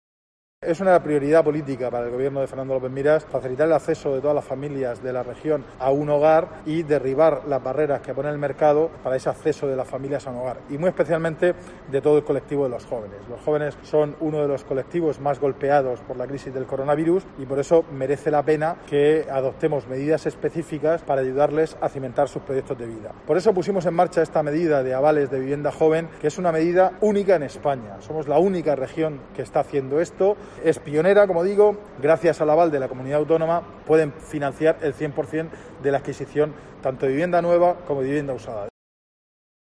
José Ramón Díez de Revenga, consejero de Fomento e Infraestructuras